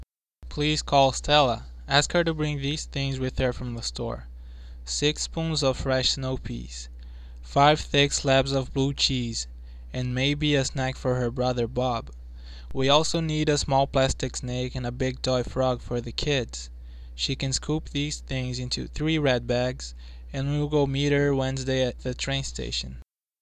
A Brazilian Accent
El orador es nativo de San Pablo, Brasil, y se observa un moderado acento en su pronunciación.
BRAZIL.mp3